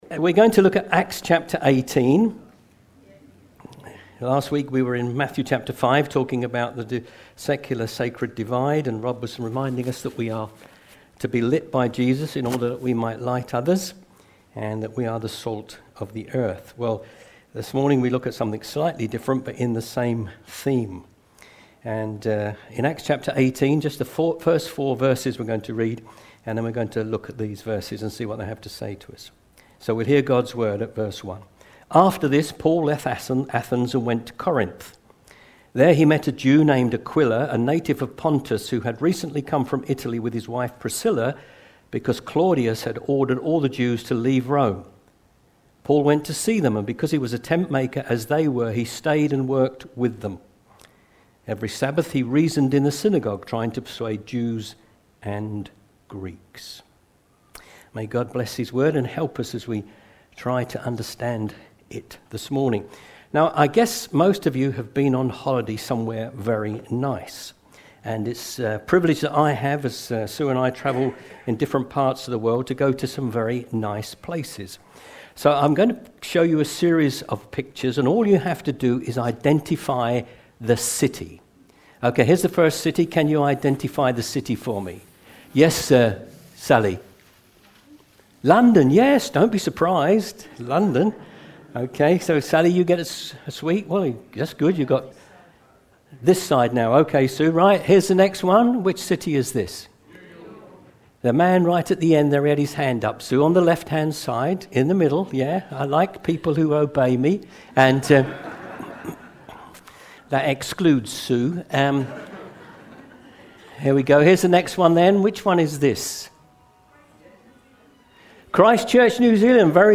Twynham Church Menu Services New to Church?
Sermon